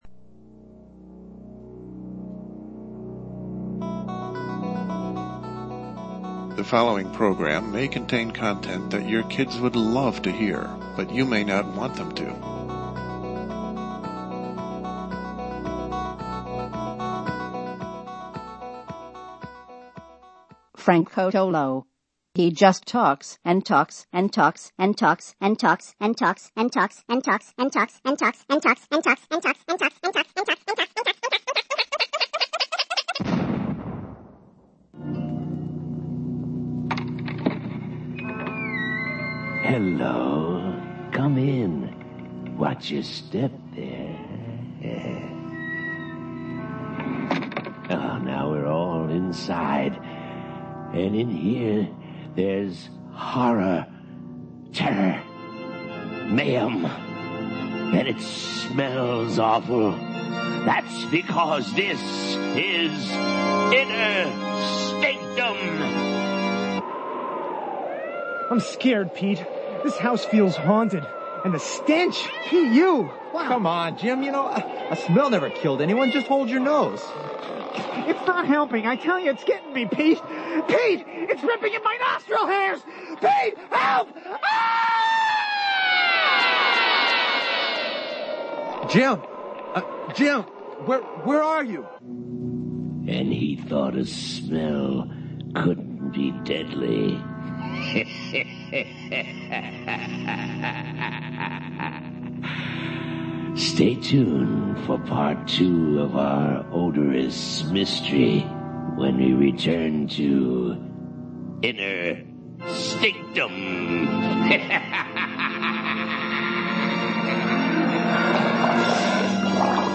LIVE, Thursday, June 21 at 9 p.m.